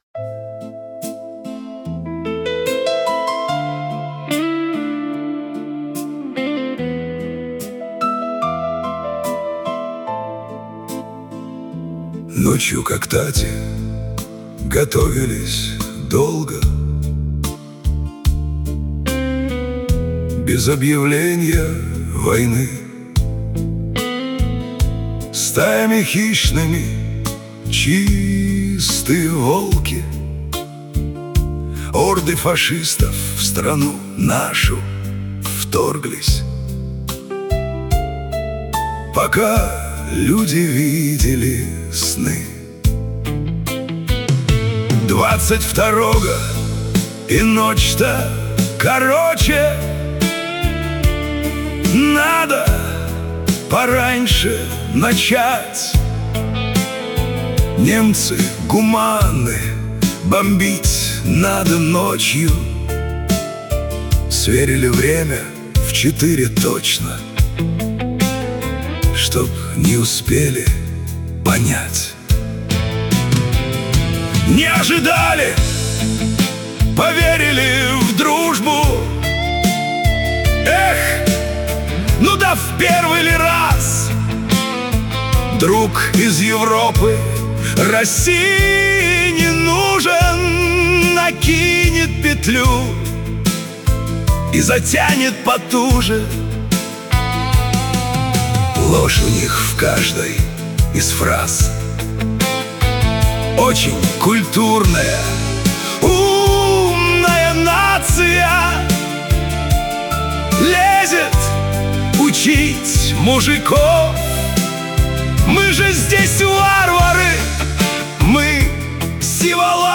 • Аранжировка: Ai
• Жанр: Военная